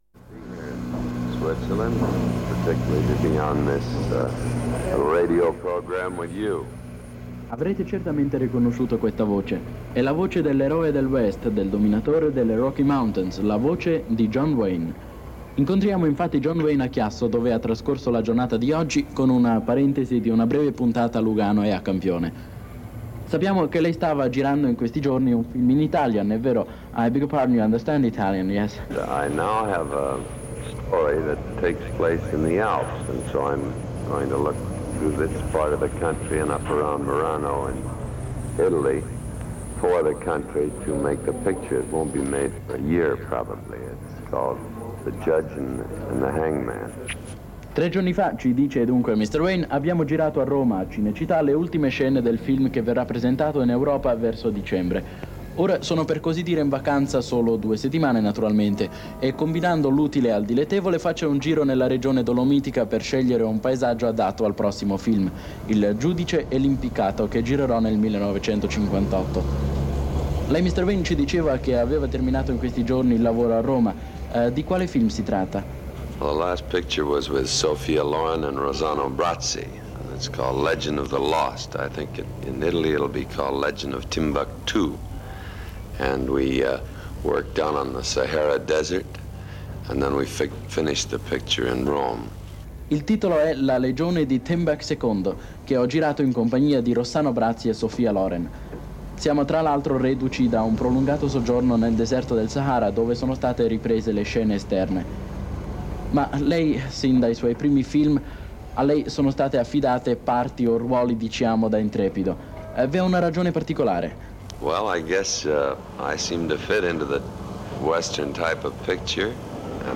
John Wayne intervistato a Chiasso (1957)
Momenti straordinari e piacevoli consuetudini da rivivere attraverso questa selezione di documenti d'archivio degli anni Cinquanta.